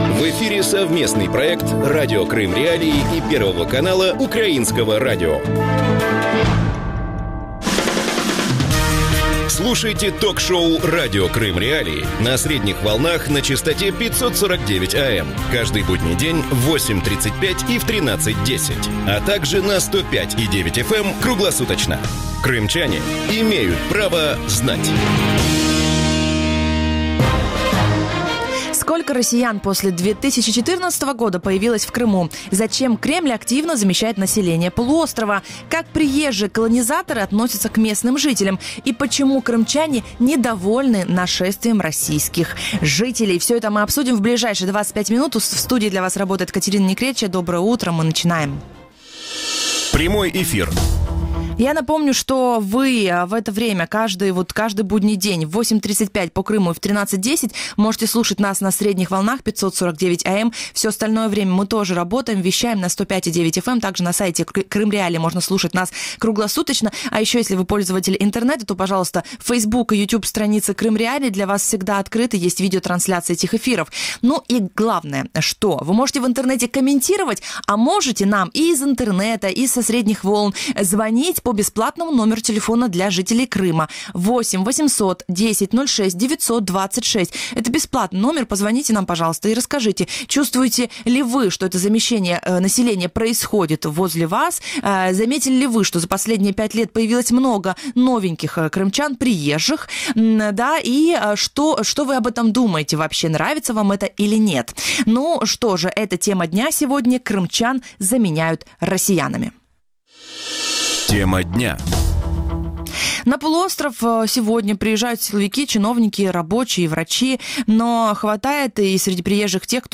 И почему крымчане недовольны нашествием российских переселенцев? Гости эфира: